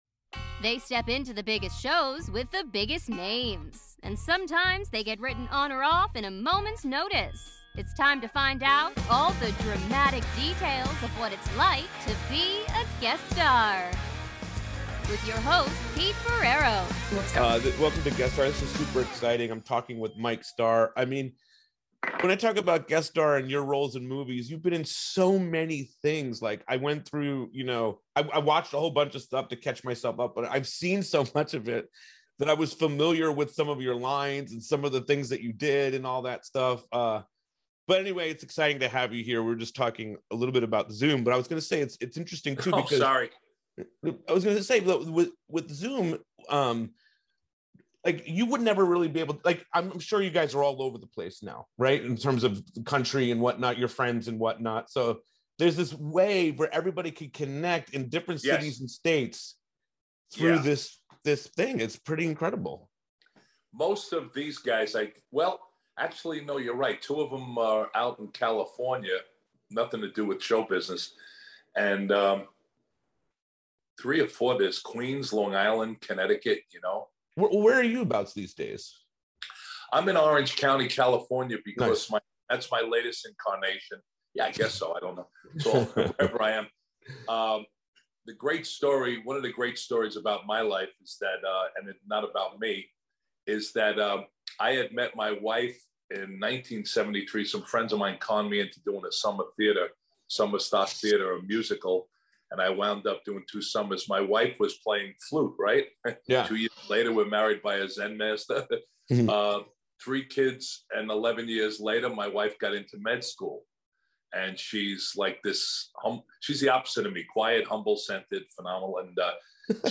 2 part interview. In the first part they talk about the beginning, The Natural and Dumb and Dumber. Mike has a lot of incredible stories.